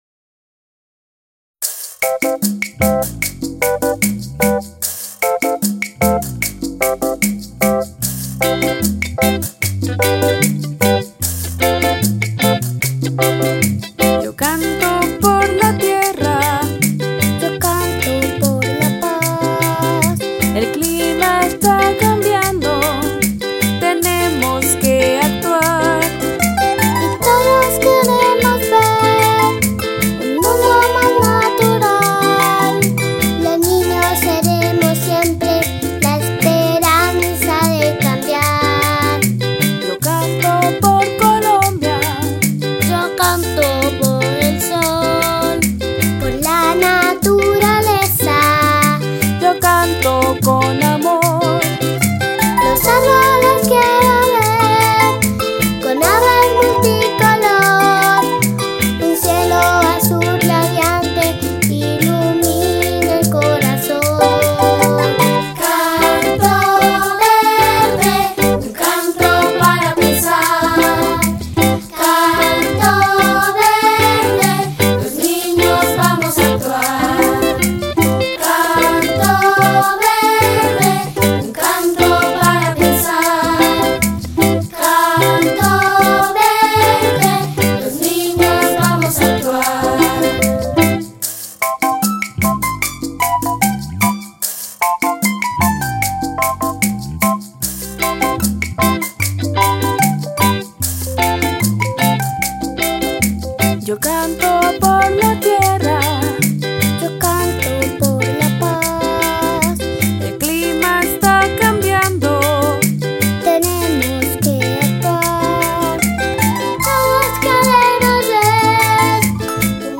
Calypso